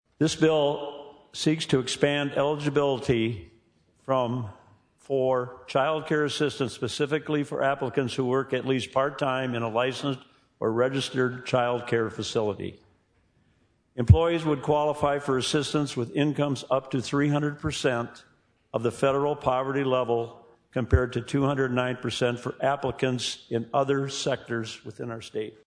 Rep. Jack Kolbeck, R-Sioux Falls, outlined specific financial concerns about expanding the program without additional funding.